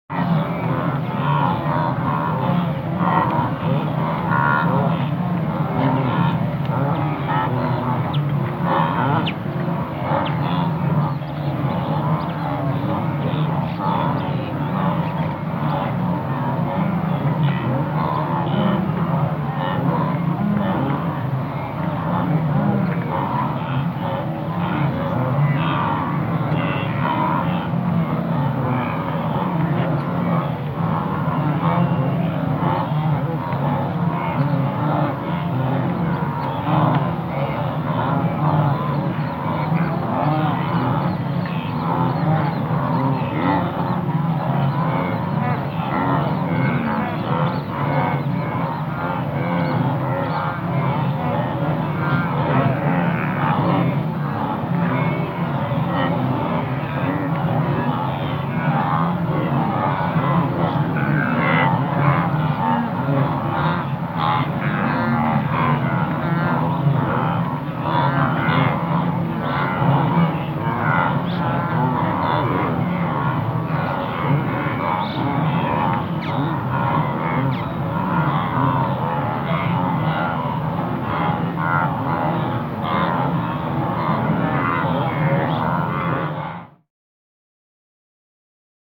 دانلود آهنگ گاو وحشی 11 از افکت صوتی انسان و موجودات زنده
جلوه های صوتی
دانلود صدای گاو وحشی 11 از ساعد نیوز با لینک مستقیم و کیفیت بالا